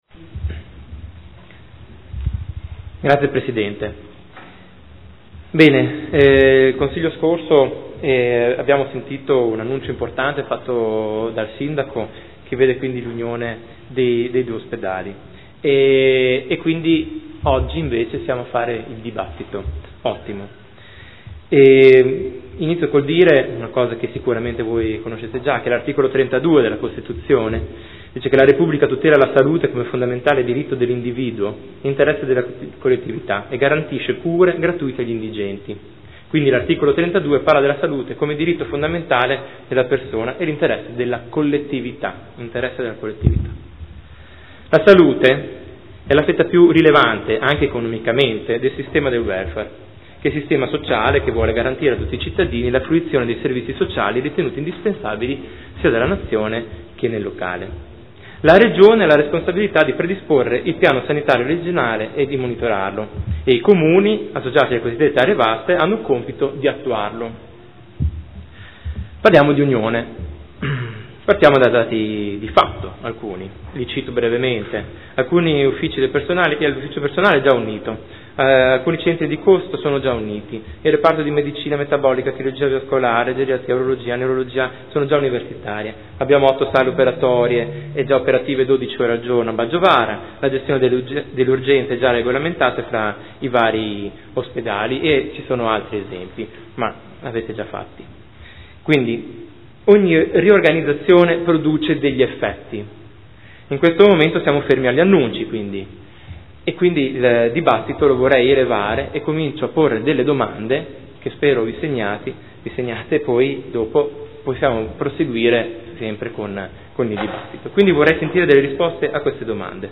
Seduta del 23/07/2015 Dibattito sulla Sanità
Audio Consiglio Comunale